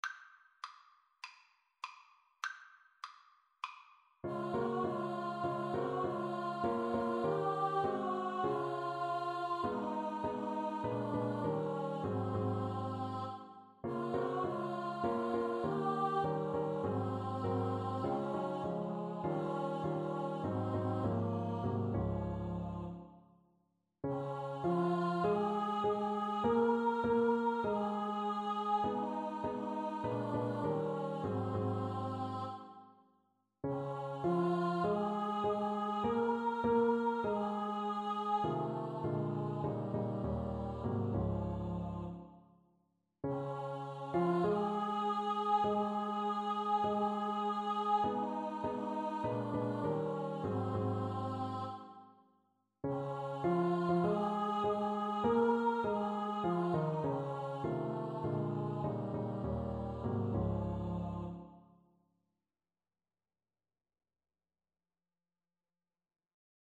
Christmas Christmas Choir Sheet Music Rise Up, Shepherd, and Follow
4/4 (View more 4/4 Music)
Db major (Sounding Pitch) (View more Db major Music for Choir )
Choir  (View more Easy Choir Music)
Traditional (View more Traditional Choir Music)